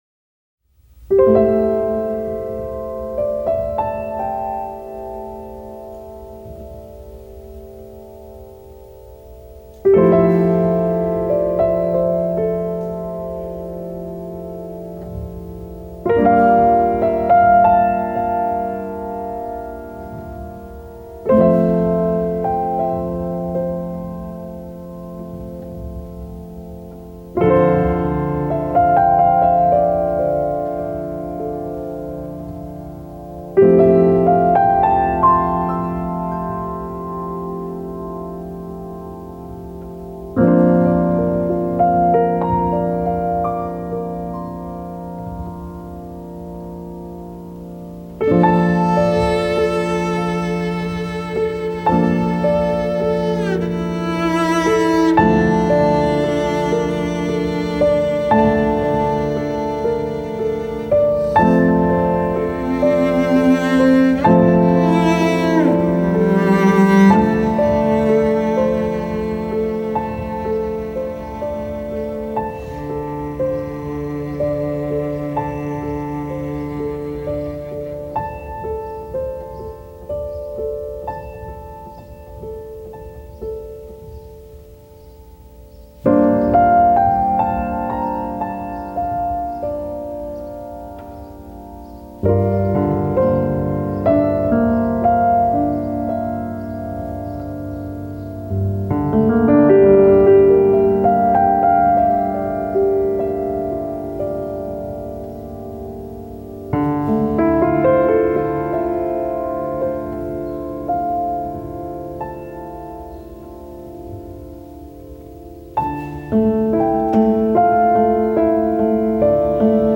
آرامش بخش
Classical Crossover
پیانو